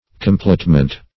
Complotment \Com*plot"ment\